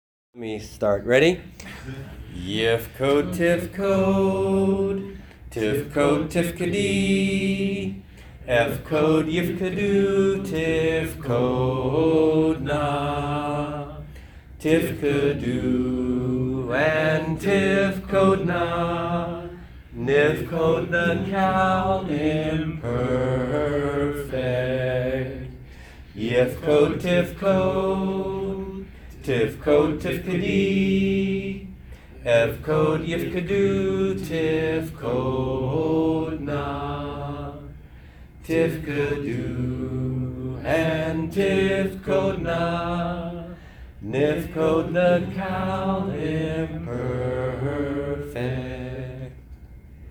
[3] Memory Song on Qal Impf conjugation (tune: “O When the Saints”):